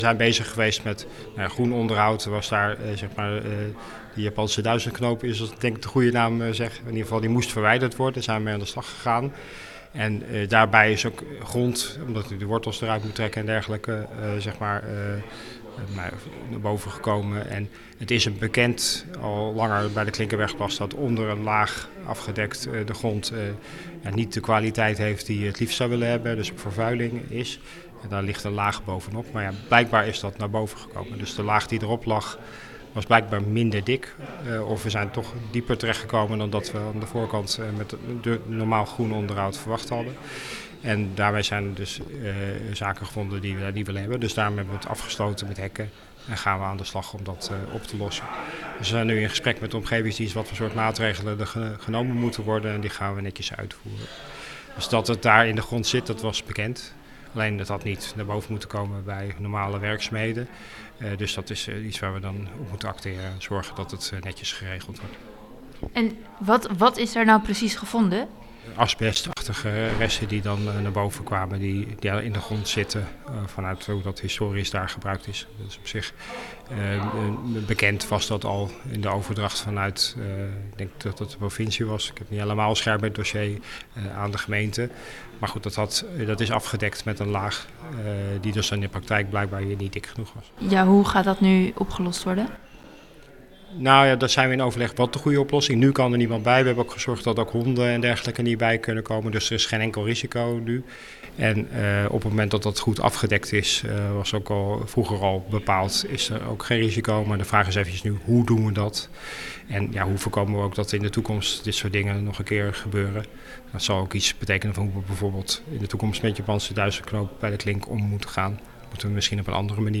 Wethouder Elfred Bus over de asbestvervuilde grond die gevonden is bij de Klinkenbergerplas in Oegstgeest.